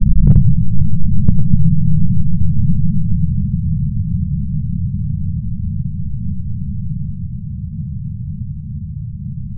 Amiga 8-bit Sampled Voice
1 channel
Snare.mp3